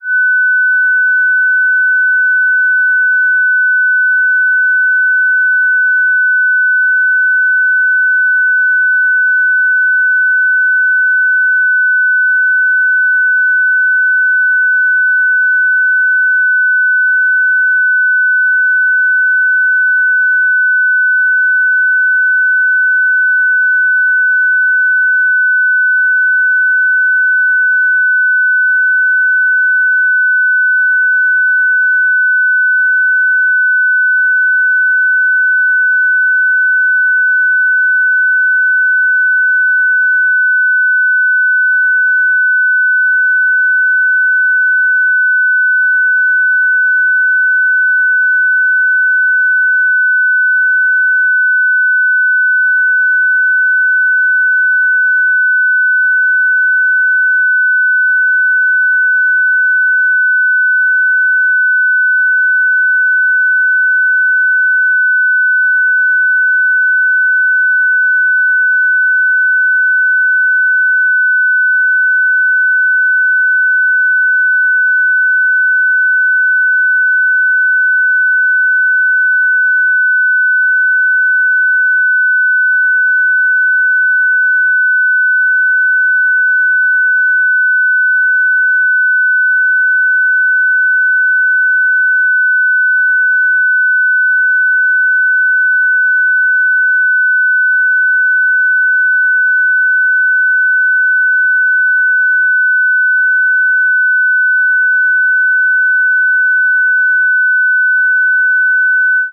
FST4, 120-sec mode
FST4-120.ogg